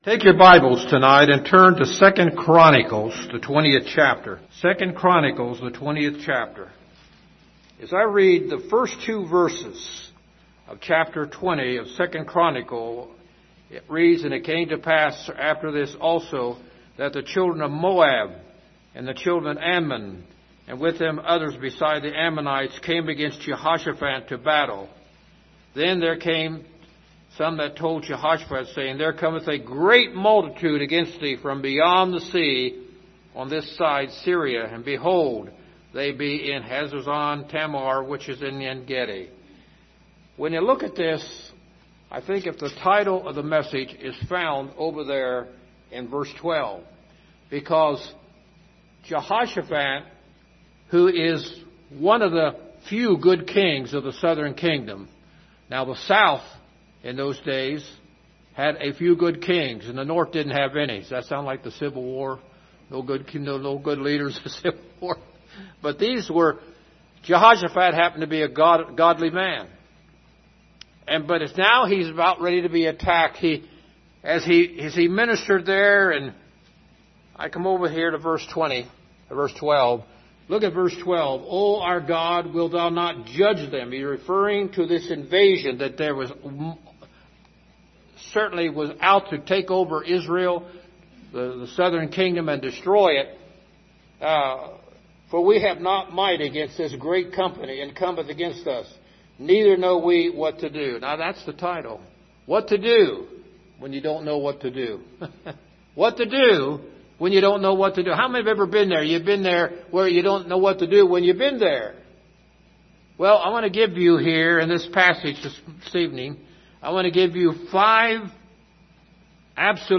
General Passage: 2 Chronicles 20:12 Service Type: Sunday Evening « A Desperate Mother’s Divine Appointment Truth